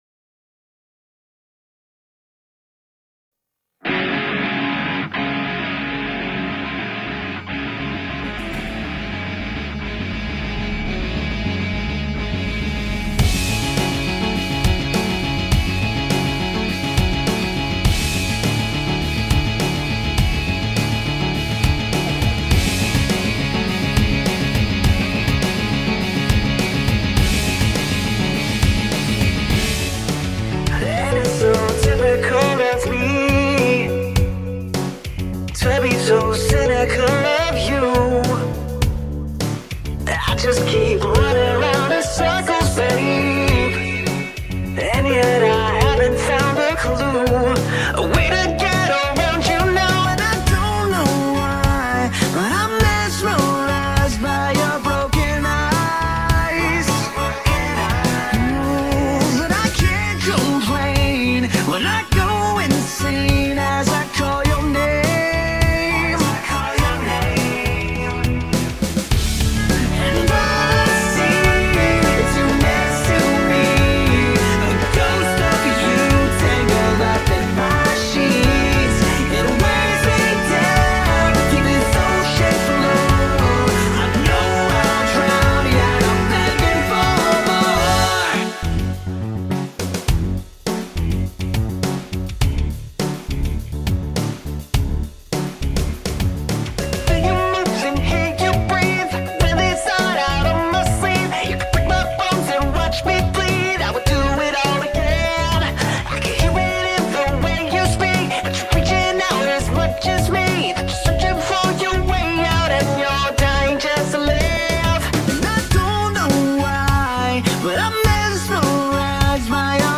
(Warning: this is more of a work of art than it is music.)